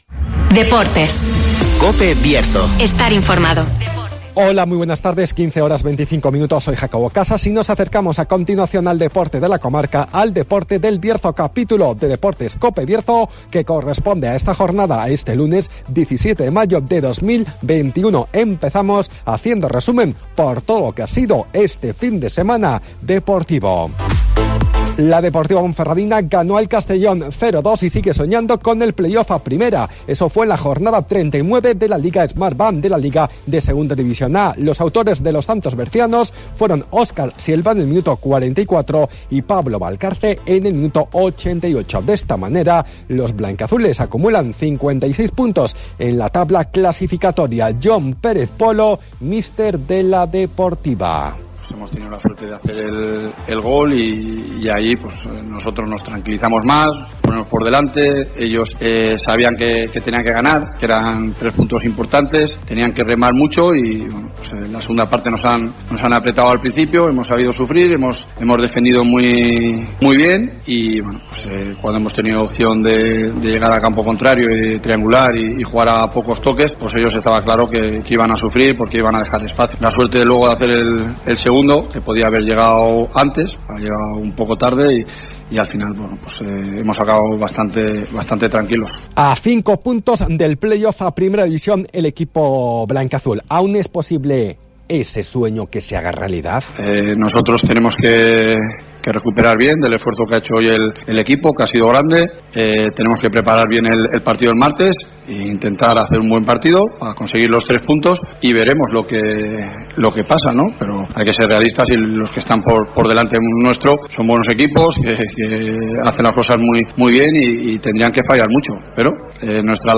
DEPORTES